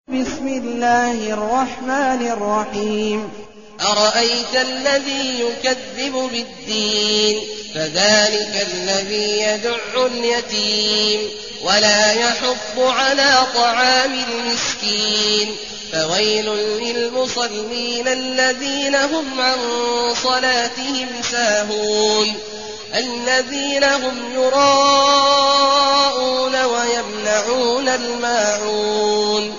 المكان: المسجد الحرام الشيخ: عبد الله عواد الجهني عبد الله عواد الجهني الماعون